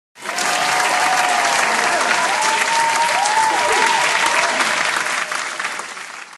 Audience Clapping